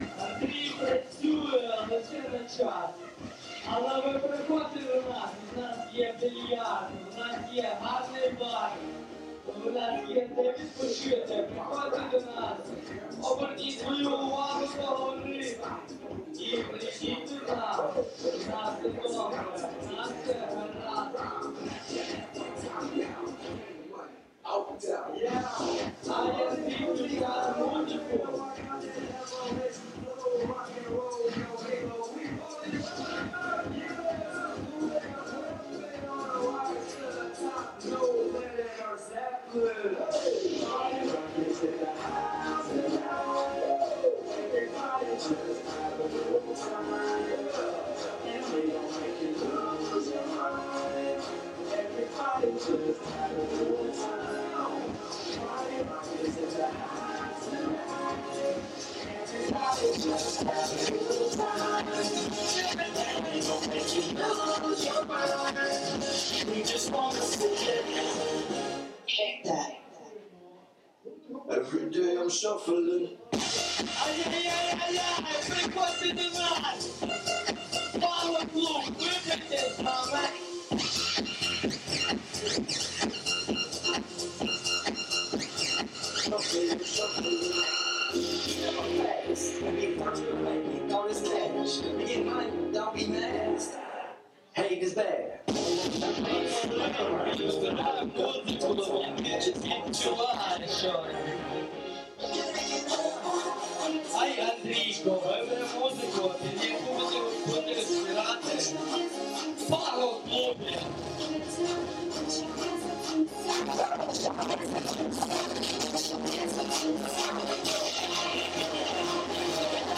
Присоединяйтесь к нам для вечернего праздника музыки в караоке-клубе “PAGO-2024”, расположенном на живописной Аркадийской аллее.
Караоке вечори в Одесі